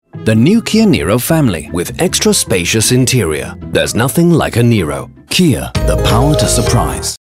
Middle Aged
Commercial
My voice is friendly, trustworthy, and naturally conversational, making it well suited to brands and organisations looking to communicate clearly and authentically.